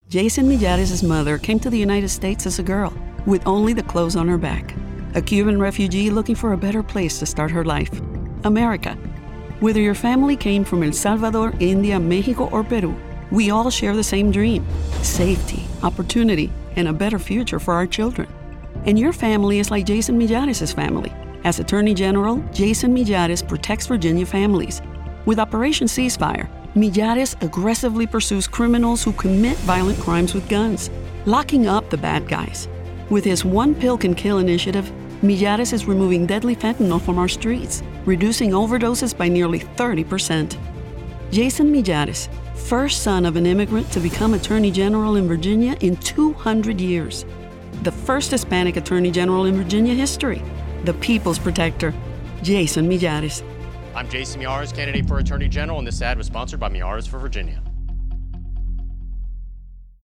English Political Spot